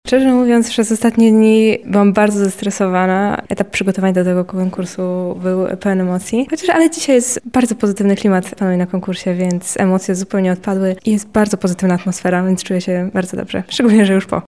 studenci sonda